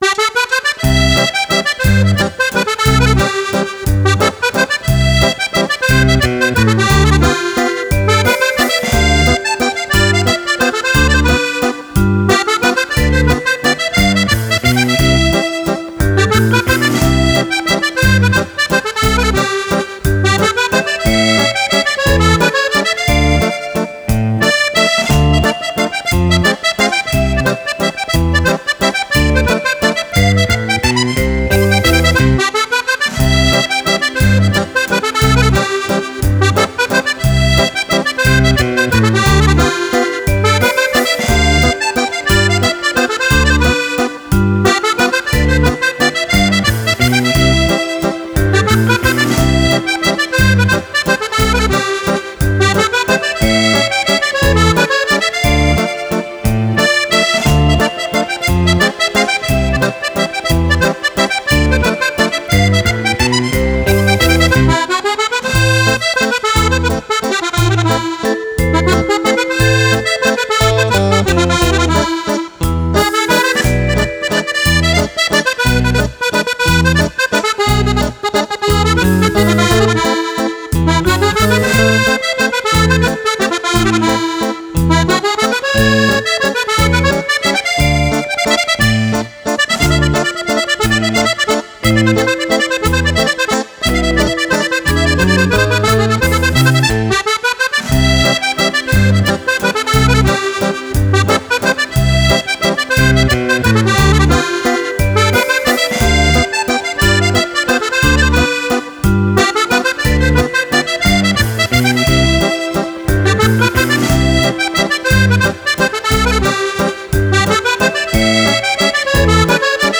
Valzer
e 12 ballabili per Fisarmonica solista